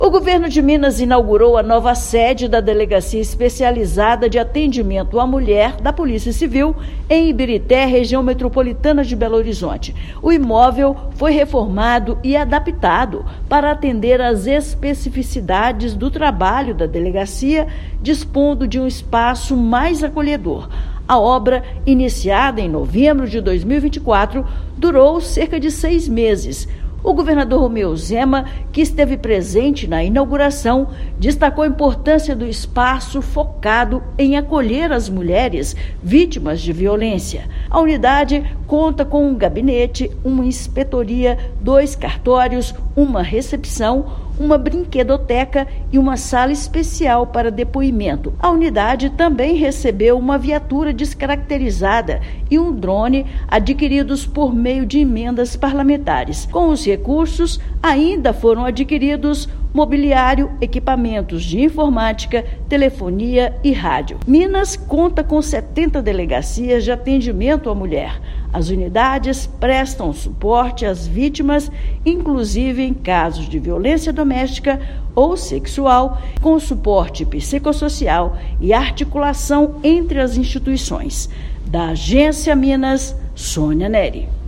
Unidade vai proporcionar acolhimento mais eficiente e humanizado às mulheres em situação de violência. Ouça matéria de rádio.